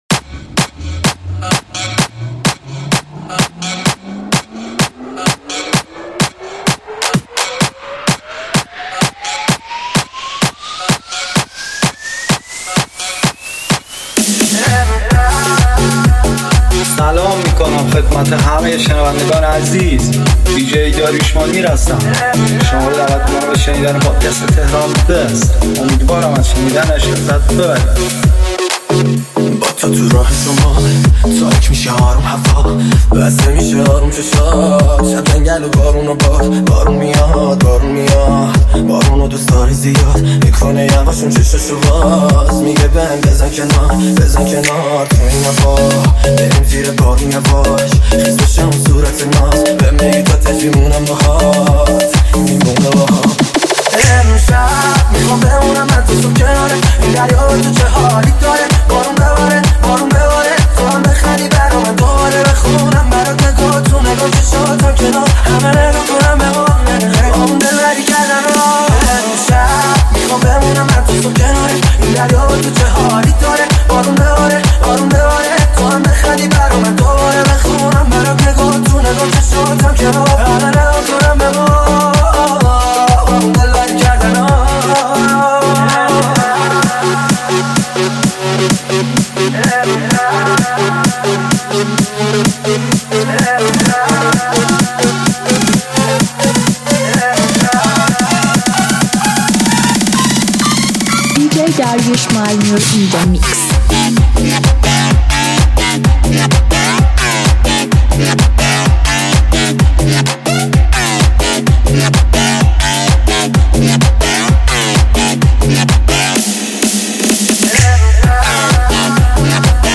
ریمیکس مخصوص باشگاه
میکس شاد مخصوص ماشین